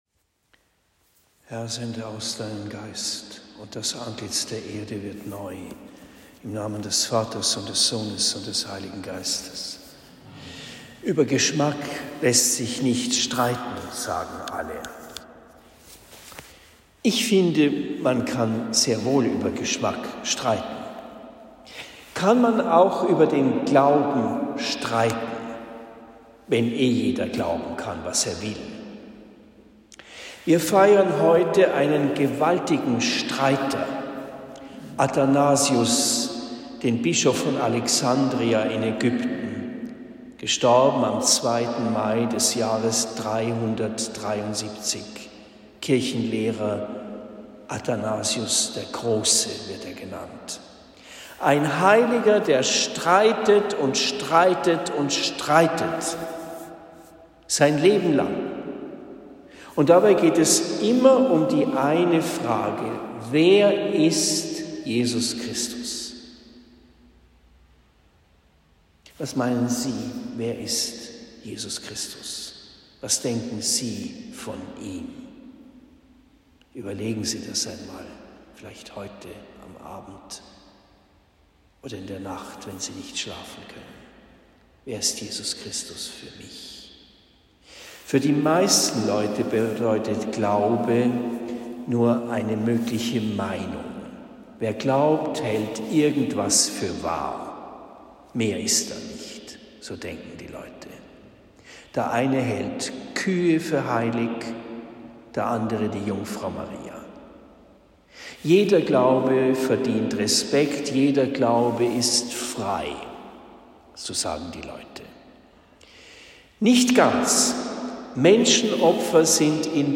Predigt in Homburg St. Burkhard am 02. Mai 2023